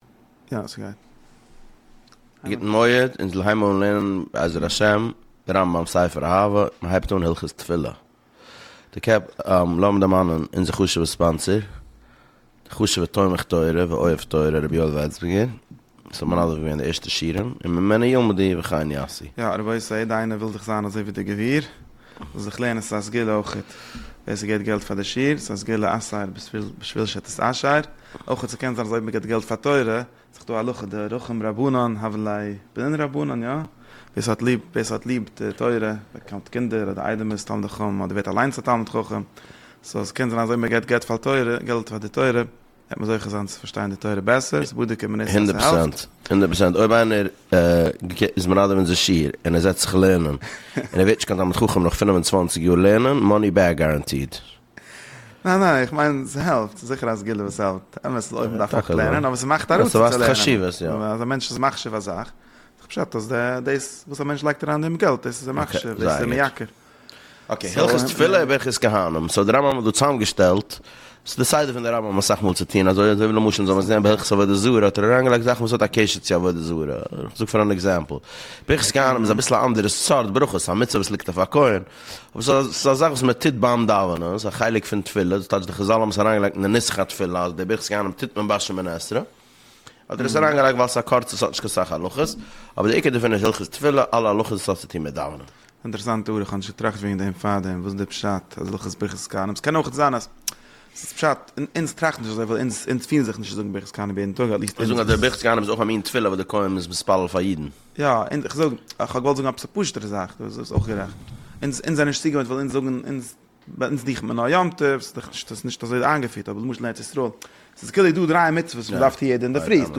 שיעורים על הרמב"ם פרק אחד ליום